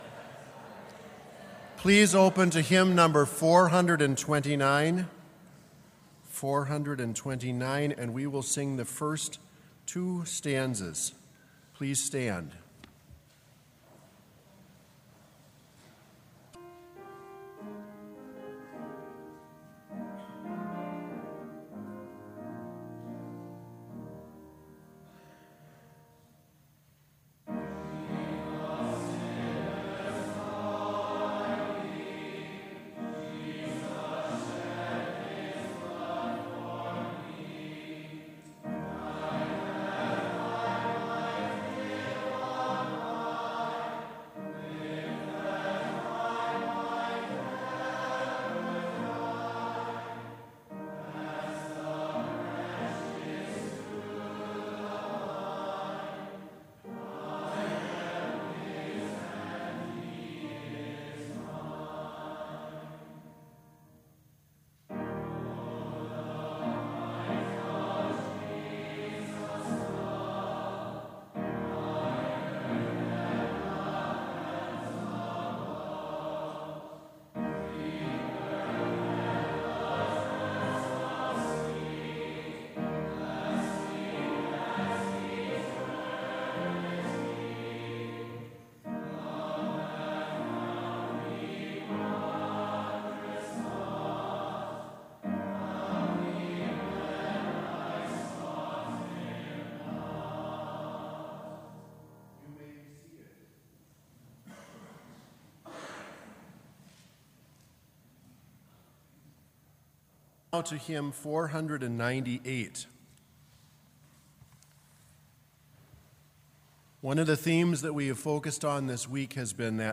Complete service audio for Chapel - October 10, 2019